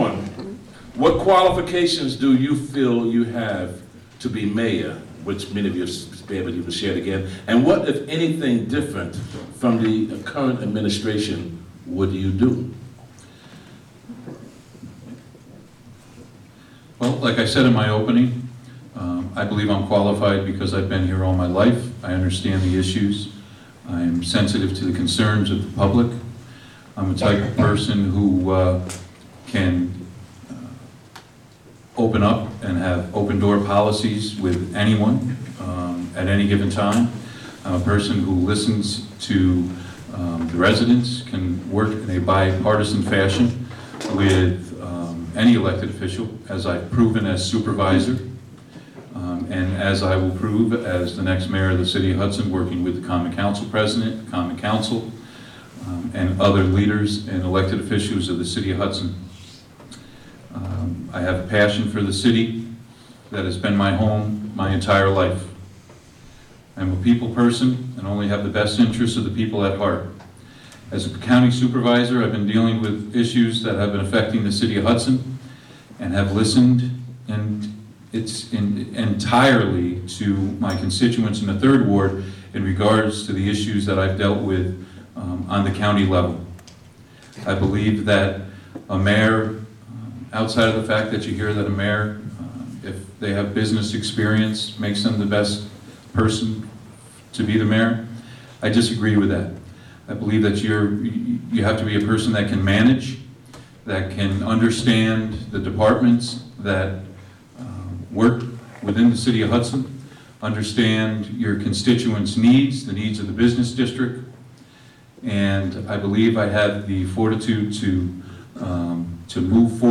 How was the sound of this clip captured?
Radio for Open Ears HAALA candidate forum broadcast live on WGXC.